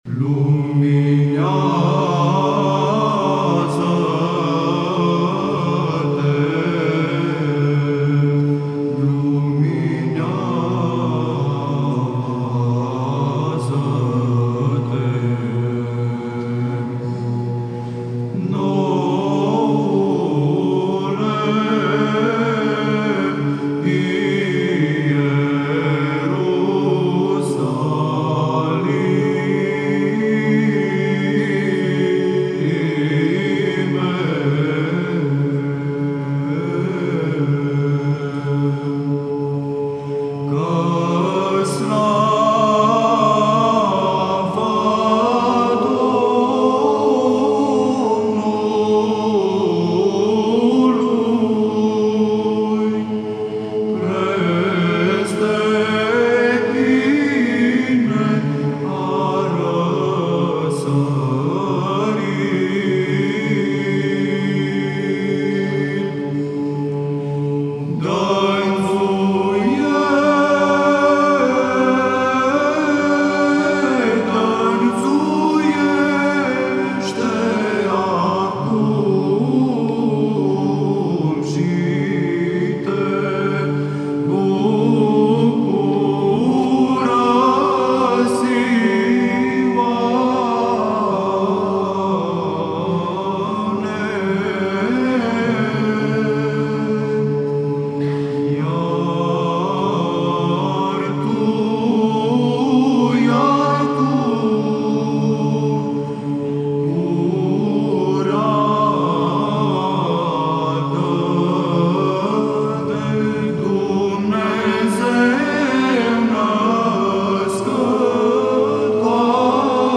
Православни песнопения на румънски